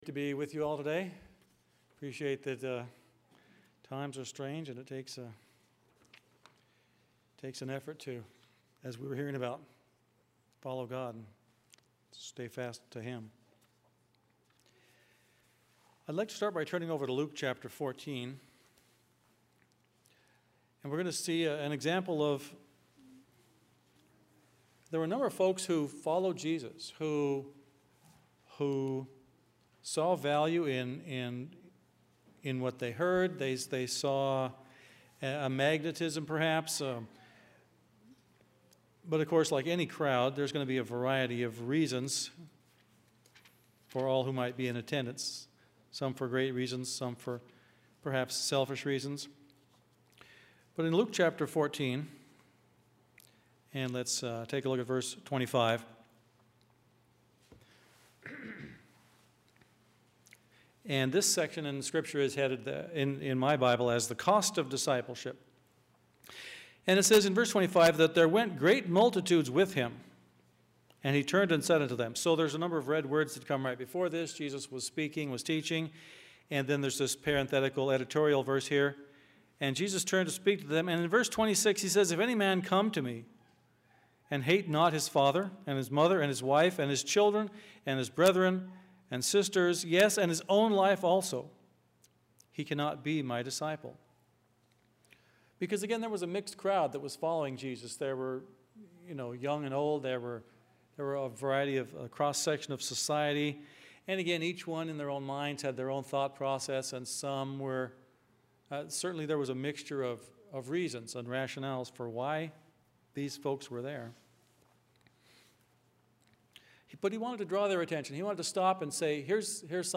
Sermon
Given in Phoenix East, AZ